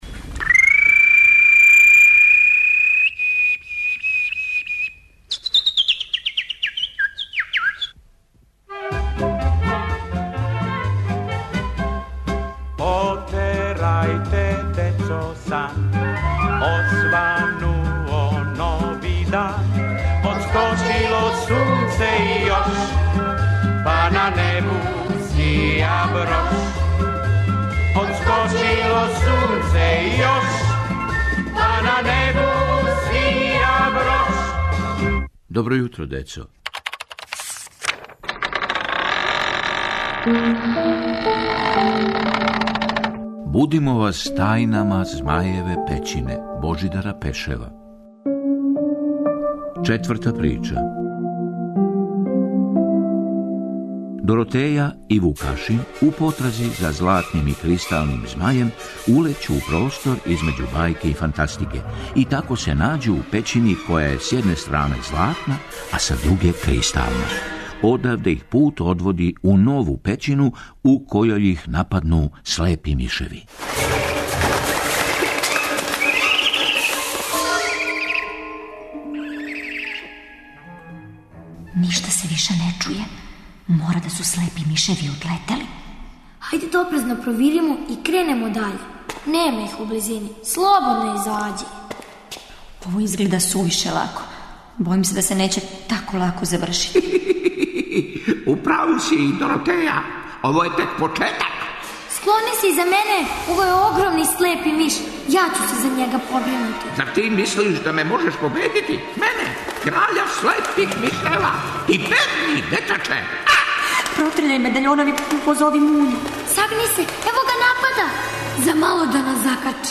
Прича за добро јутро